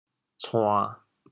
潮州 sên1 gug4 cuan1 潮阳 sên1 gug4 cuan1 潮州 0 1 2 潮阳 0 1 2